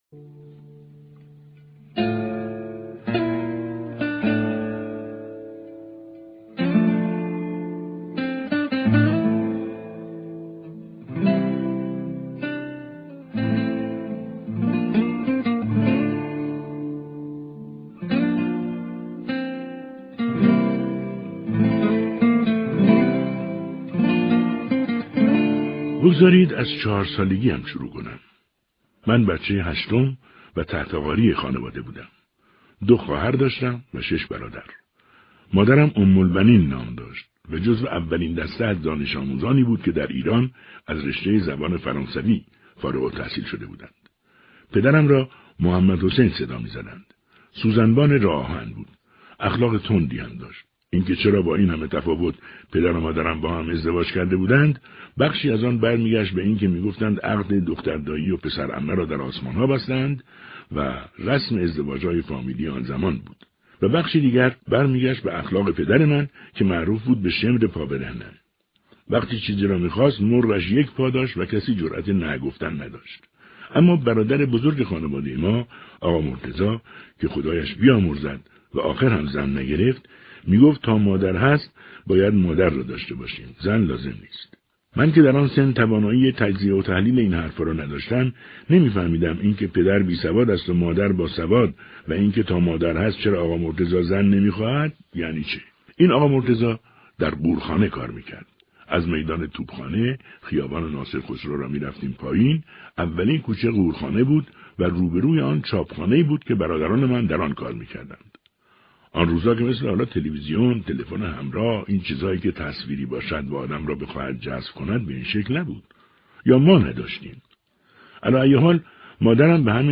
محمدعلی بهمنی شاعر نبود؛ او با شعر زندگی می‌كرد+ گوشه‌ای از زندگینامه با صدای بهروز رضوی